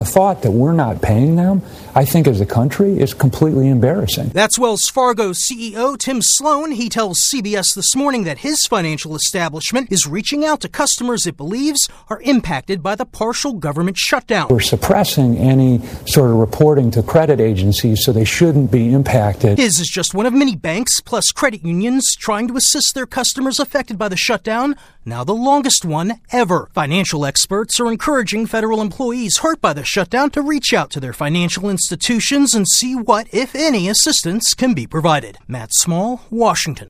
Today is the second non-payday for hundreds of thousands of federal workers impacted by the 35-day partial government shutdown. AP correspondent